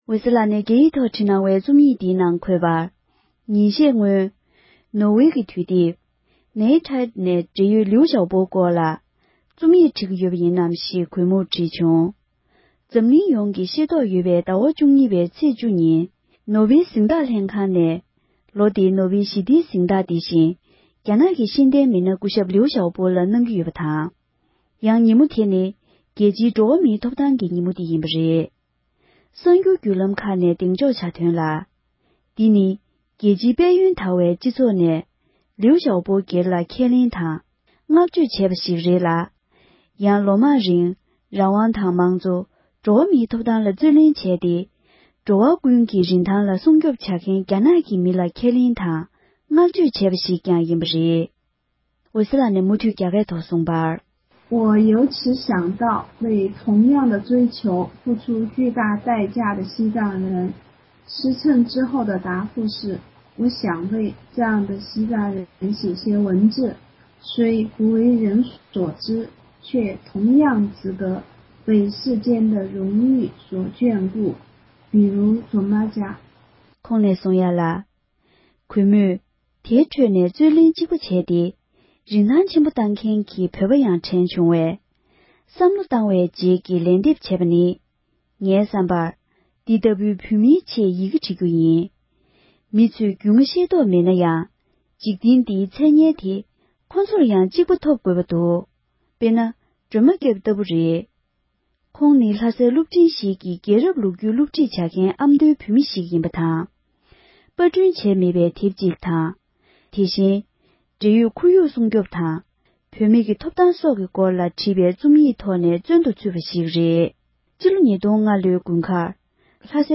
ཕབ་བསྒྱུར་དང་སྙན་སྒྲོན་ཞུས་པར་གསན་རོགས༎